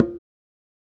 Kicks
African Drum_03.wav